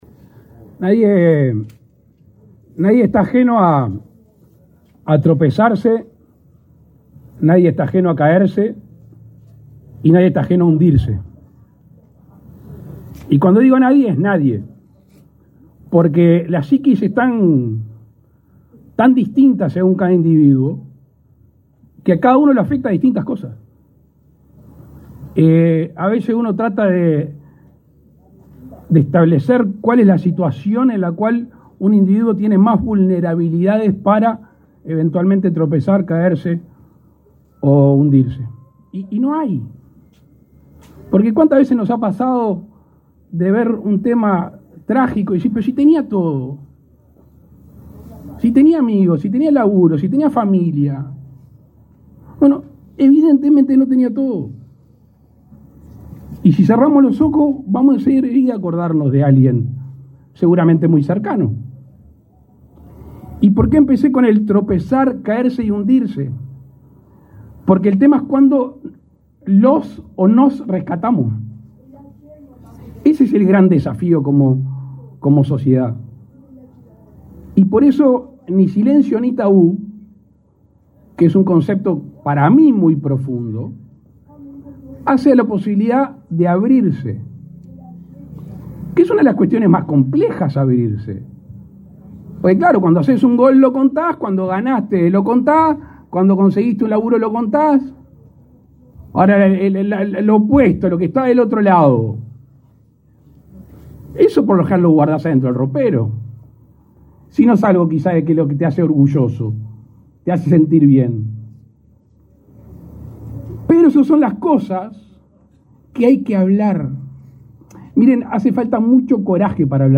Palabra del presidente de la República, Luis Lacalle Pou
Con la presencia del presidente de la República, Luis Lacalle Pou, fue inaugurado, este 31 de julio, el primer centro de salud mental Ni Silencio Ni